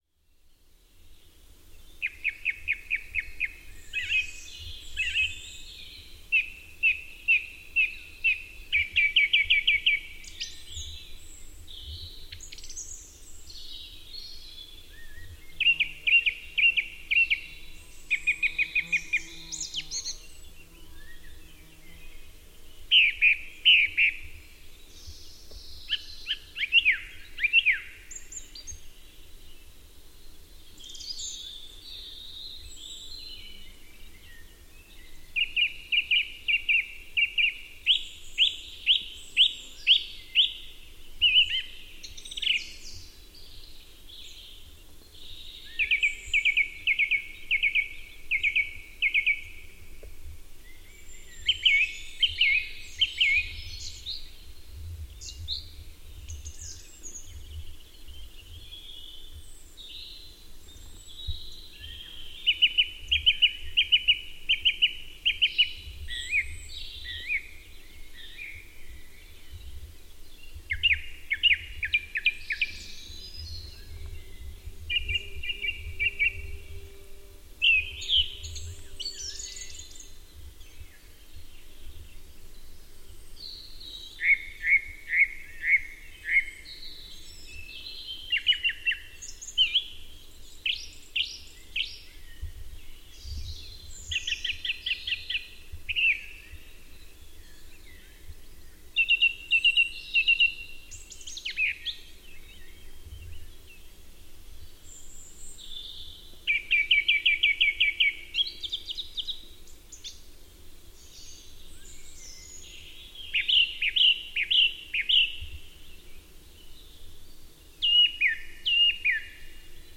鸟类推文
描述：一些鸟在春天的温带森林里唧唧喳喳。
标签： 鸣叫
声道立体声